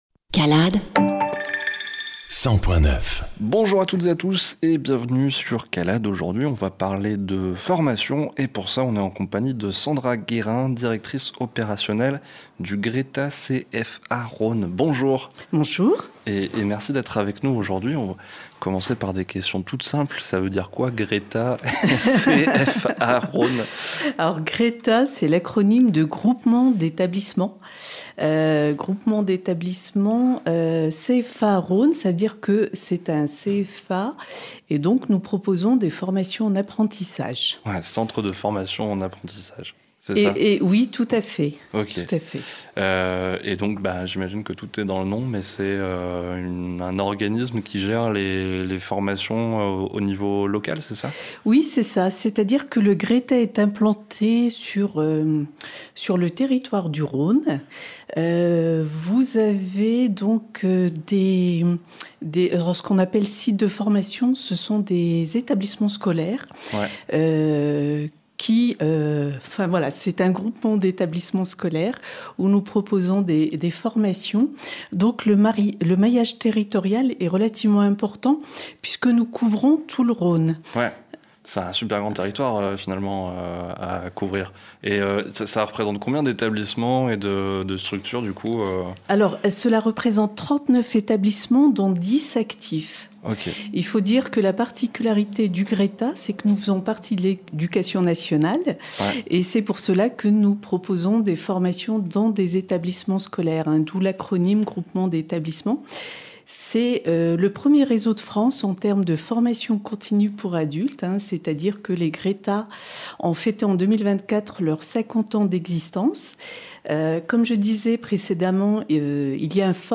calade_INTERVIEW CALADE